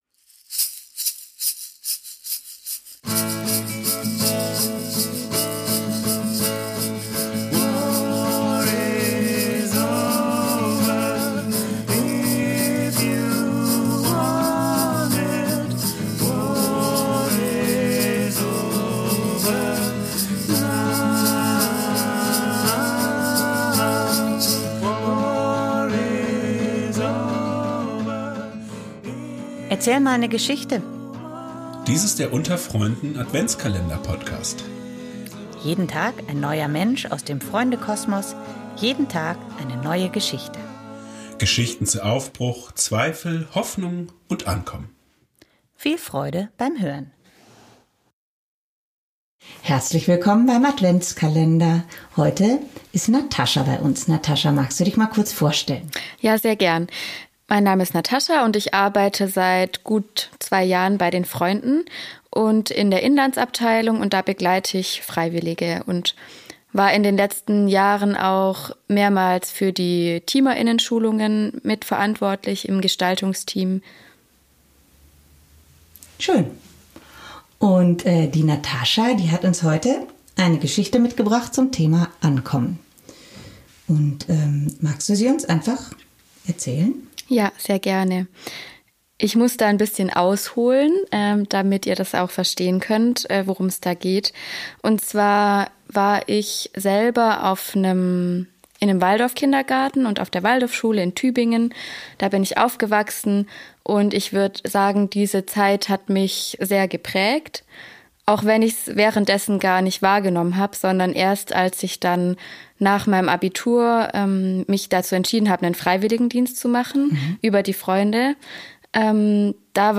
In unserem Adventskalender zum Hören erzählt jeden Tag eine andere